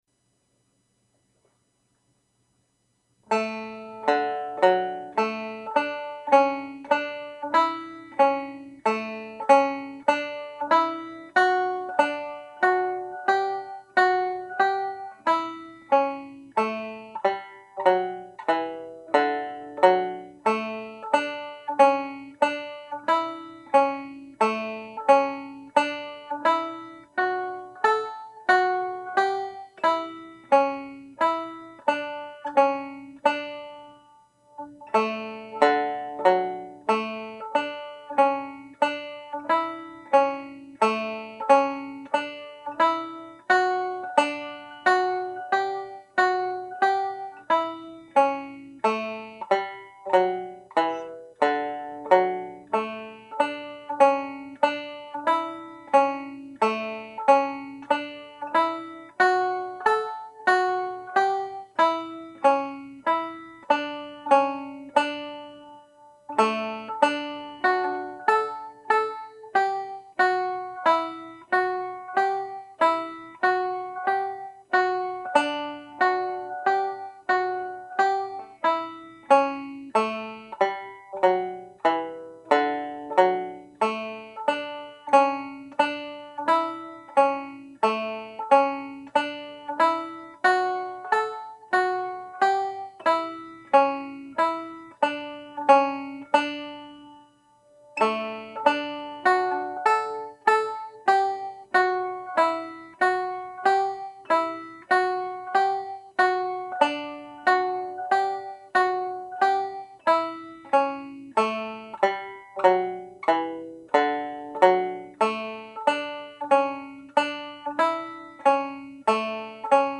(D Major)